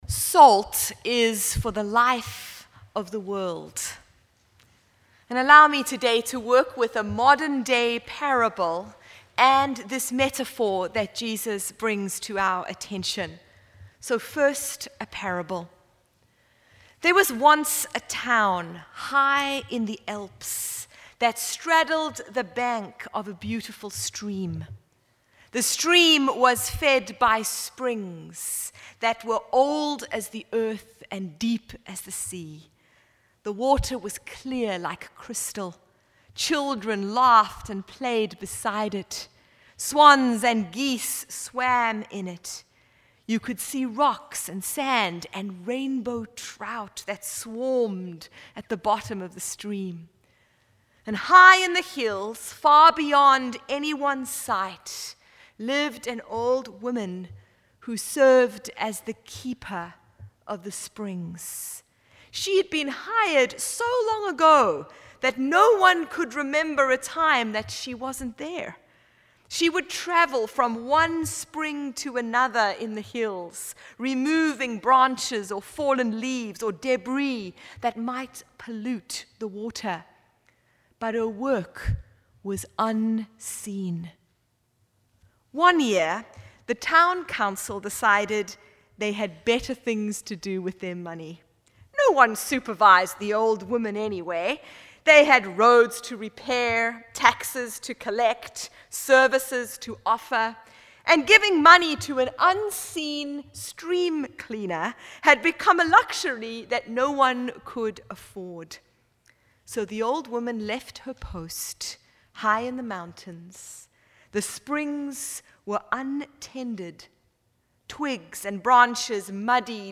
Download Download Reference Matthew 5:13-16 Sermon Notes Salt of the world_Epiphany 2020.pdf Feb 9th music.mp3 Feb 9th Theatre.mp3 Salt is a seasoning that draws little attention to itself.